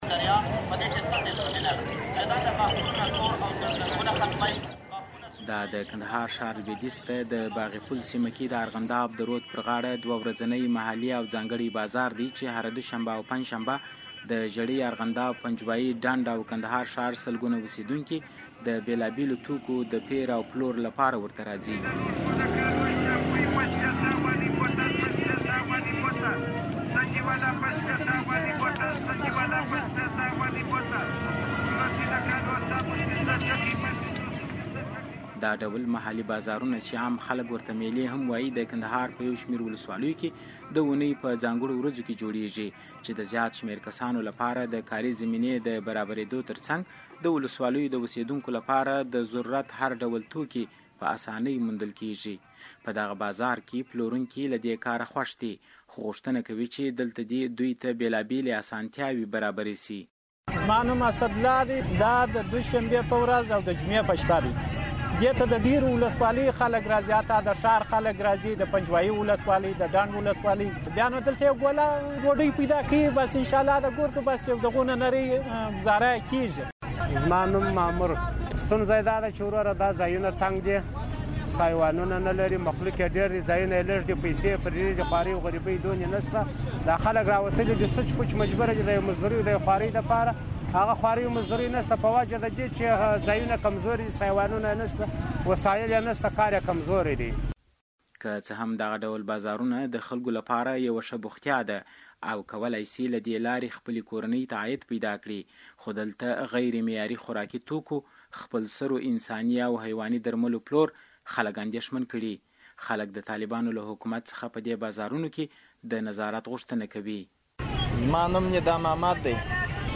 د کندهار راپور